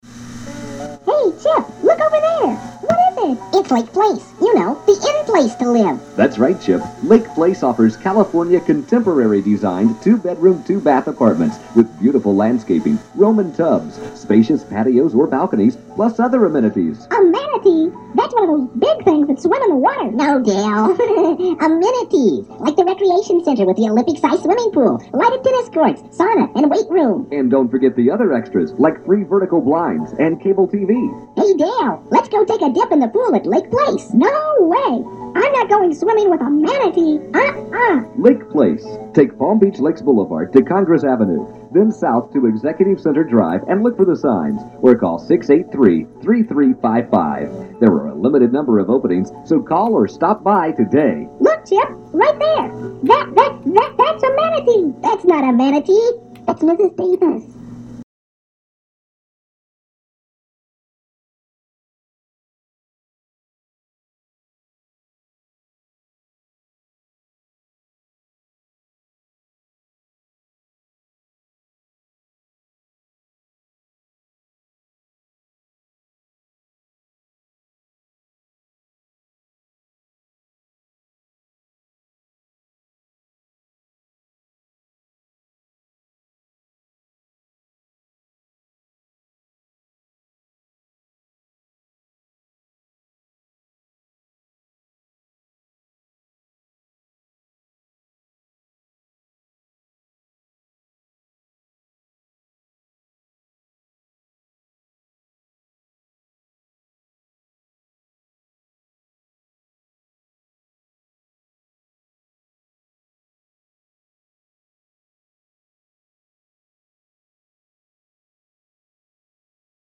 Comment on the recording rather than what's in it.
You will have to excuse the audio quality of these mid-1980's productions as they were lifted from deteriorating analog cassettes in the nick of time.